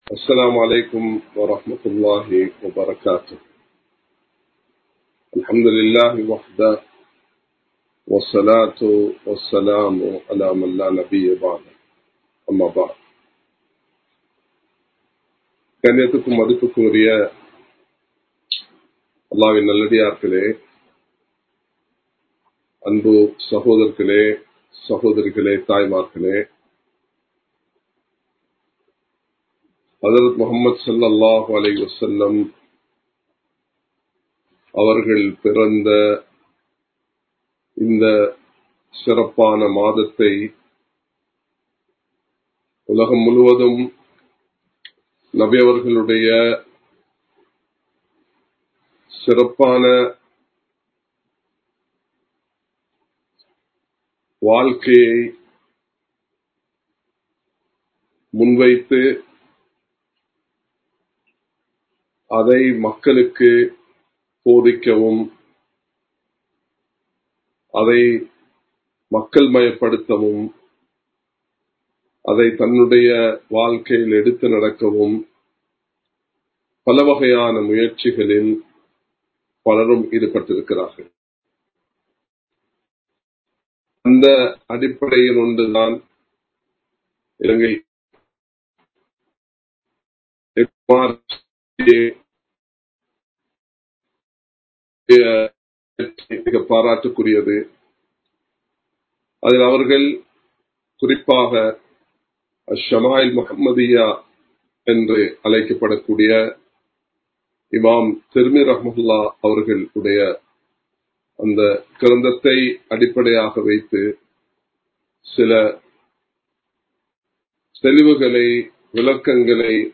நபி(ஸல்) அவர்களின் பண்புகள் (Day 01) | Audio Bayans | All Ceylon Muslim Youth Community | Addalaichenai
Live Stream